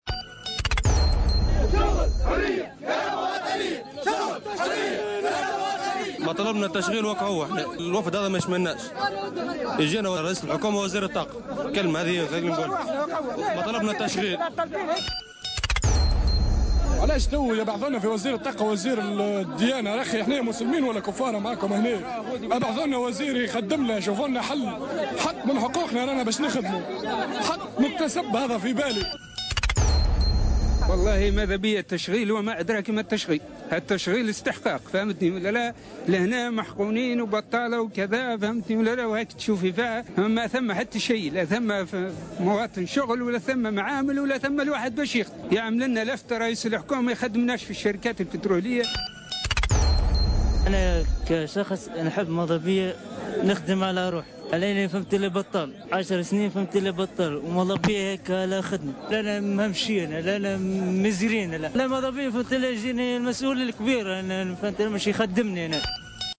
وقال وزير الشؤون الاجتماعية محمد الطرابلسي إنه سيتم الاستماع والإصغاء إلى مشاغل أهالي الجهة لإيجاد حلول جذرية لمشاكلهم.